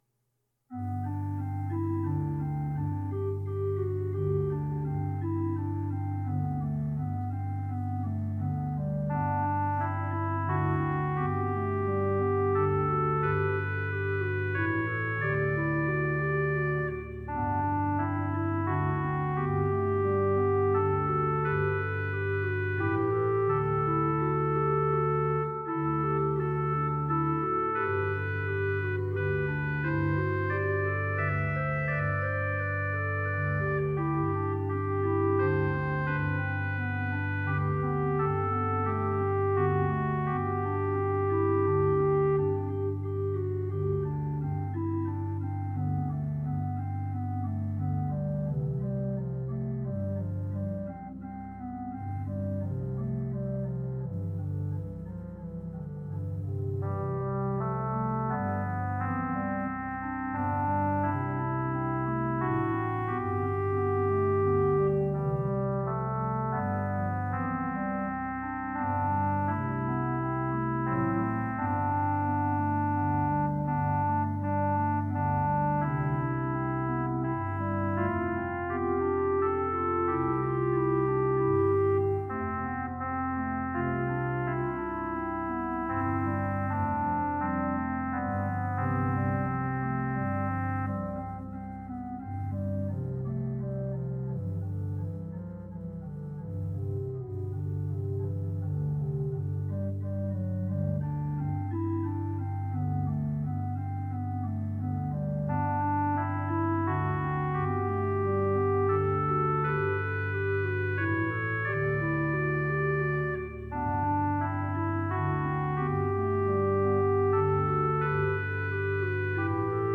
This arrangement is in trio form.